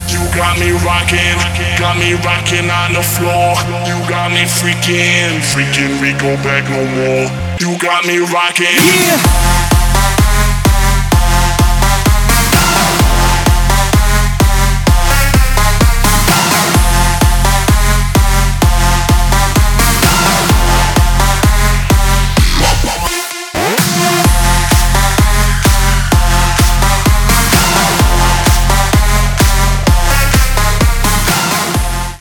• Качество: 192, Stereo
Электронный голос и классический клубный бит